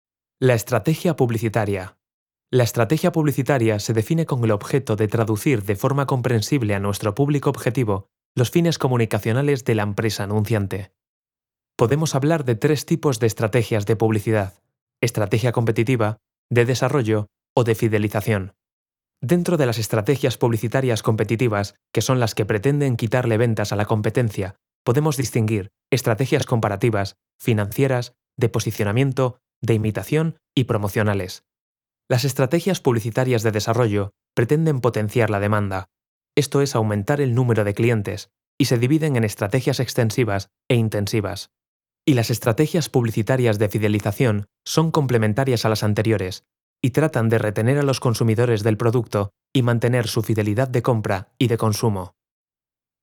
Comercial, Natural, Urbana, Cool, Cálida
E-learning
He is characterized by having a special register that can lead to different timbres and tones, and having a voice with a fairly wide tonal range.